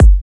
edm-kick-60.wav